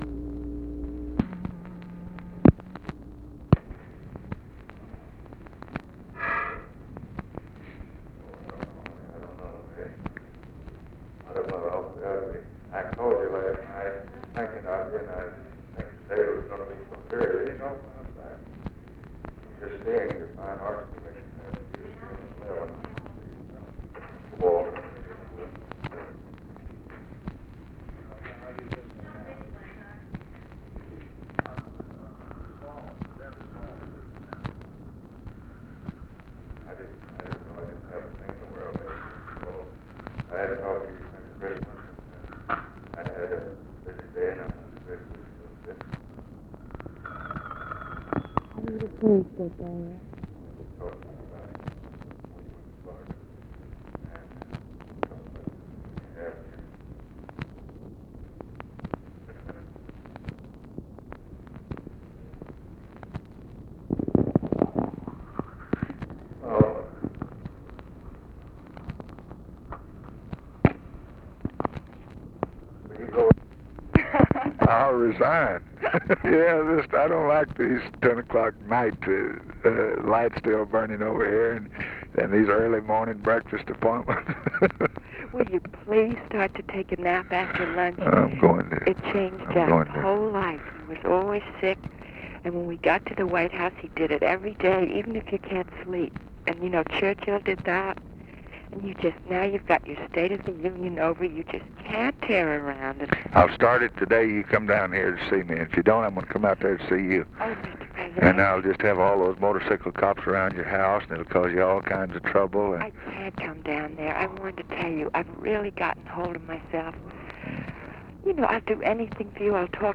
Conversation with JACQUELINE KENNEDY and OFFICE CONVERSATION, January 9, 1964
Secret White House Tapes